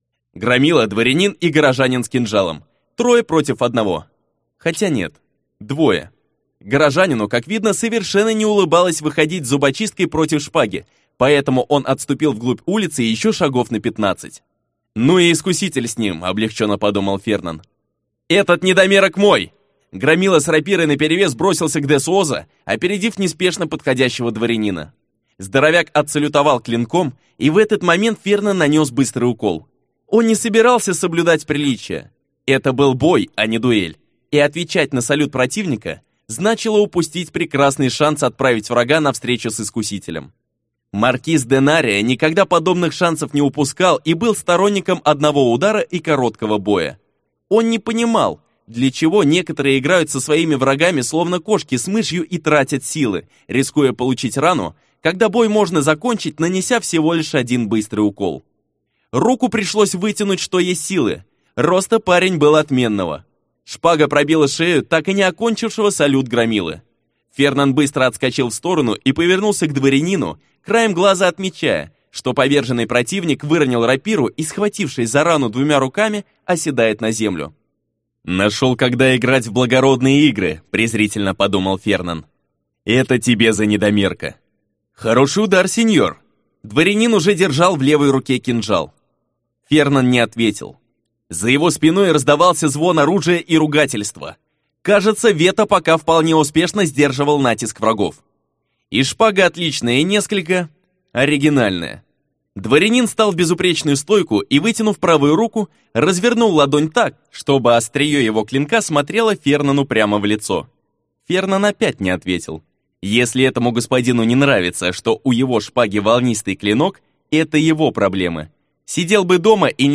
Аудиокнига Под знаком мантикоры - купить, скачать и слушать онлайн | КнигоПоиск